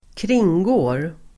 Uttal: [²kr'ing:gå:r]